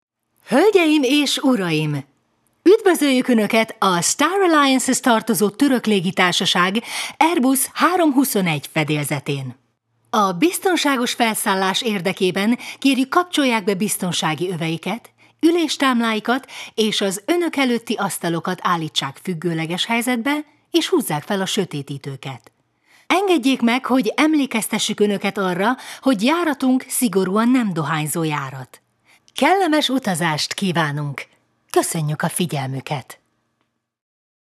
Hungarian. Actress, bright, hip, playful, experienced.